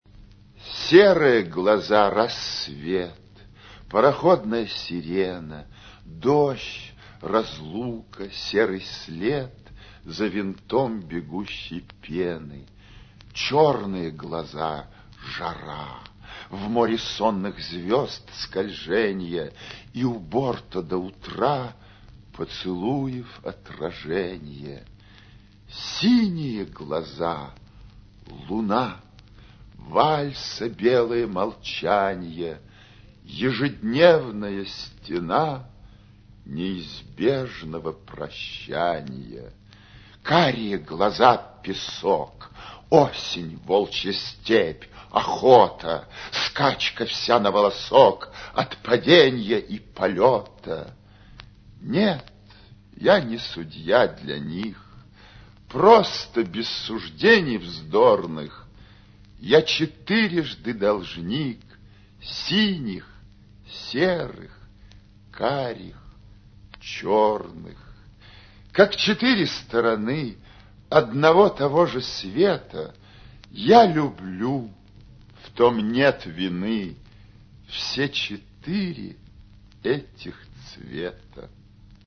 «Серые глаза — рассвет…» — читает народный артист СССР Леонид Марков